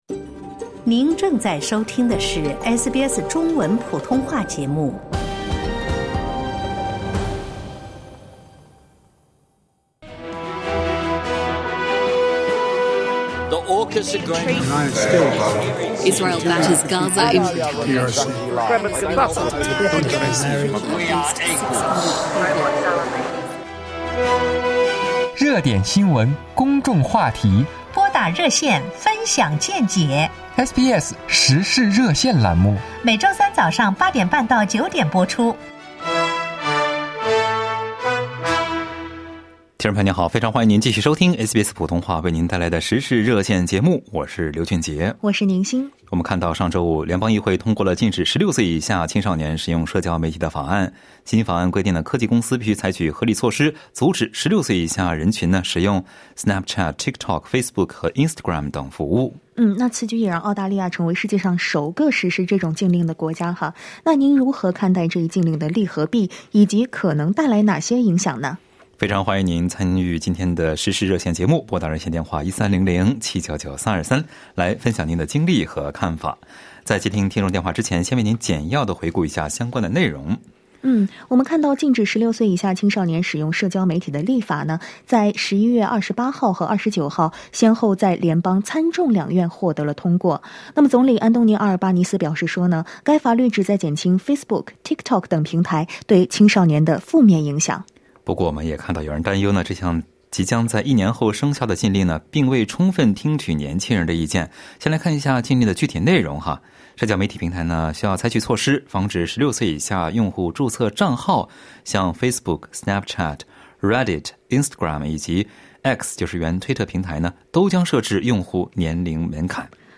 热线听众仅代表其个人观点，不代表本台立场，仅供参考。